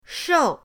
shou4.mp3